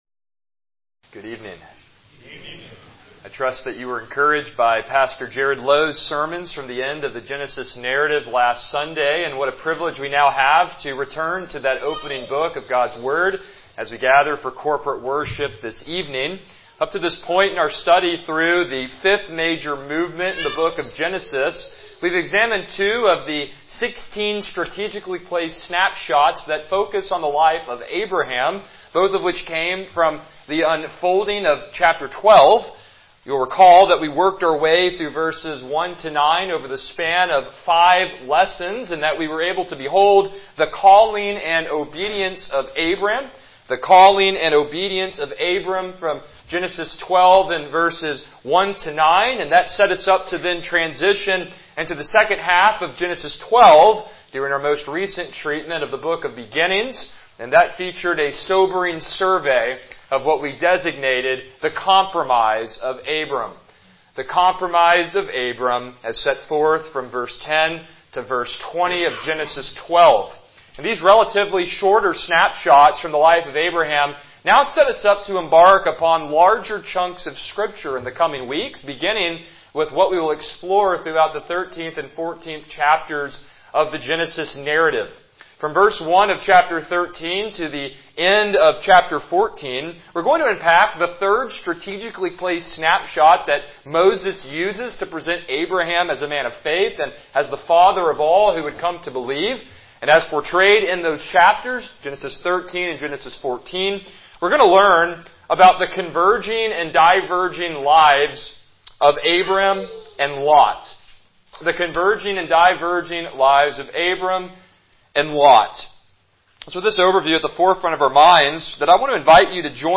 Passage: Genesis 13 Service Type: Evening Worship